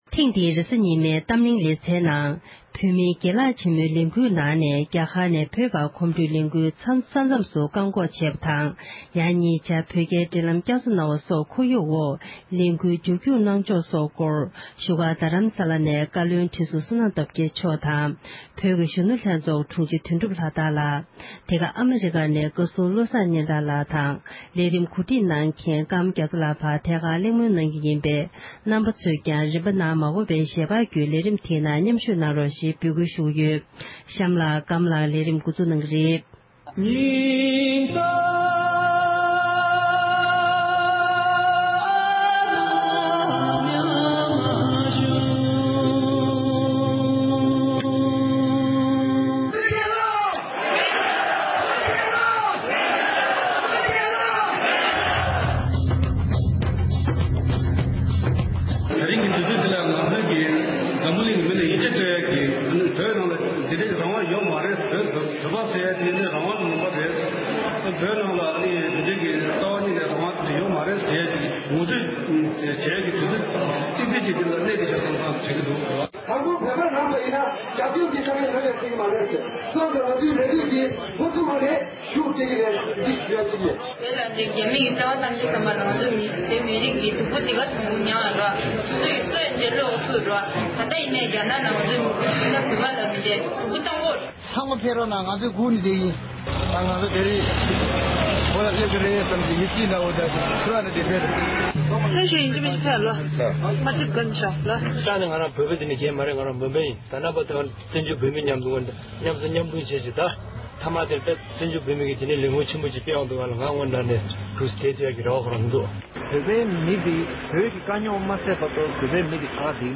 གོམ་བགྲོད་པ་རྣམས་རྒྱ་གར་ཉེན་རྟོག་པས་རྒྱ་གར་གྱི་ས་མཚམས་སུ་བཀག་ཡོད་པ་སོགས་ཀྱི་ཐོག་བགྲོ་གླེང༌།